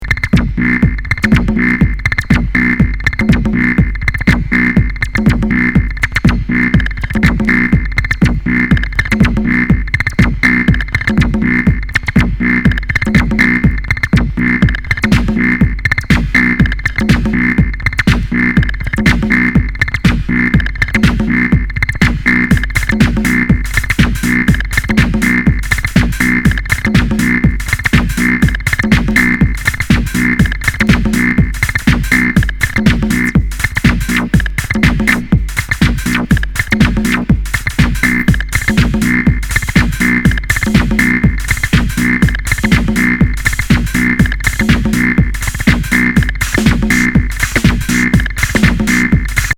インナー・トリッピーなアシッド・デトロイト!
リマスター盤。